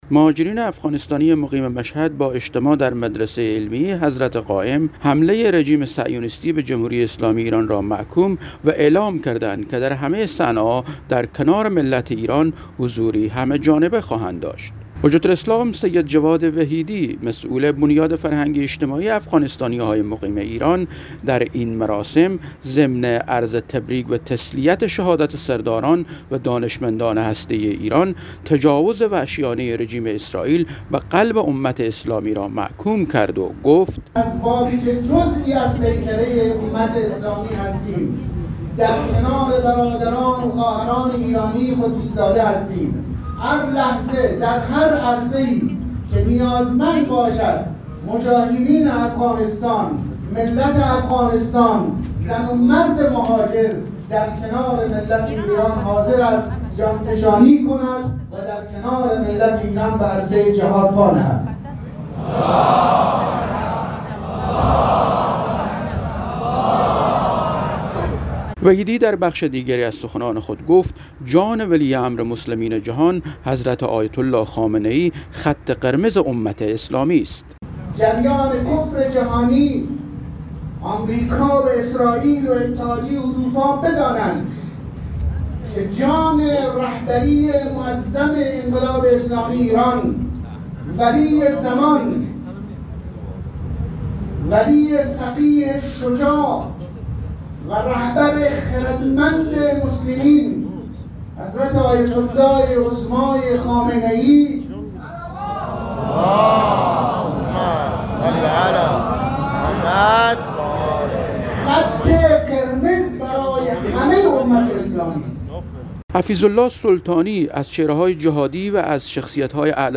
مهاجرین افغانستانی مقیم مشهد با اجتماع در مدرسه علمیه حضرت قائم (عج) اعلام کردند که در همه صحنه ها در کنار ملت ایران حضوری همه جانبه خواهند داشت.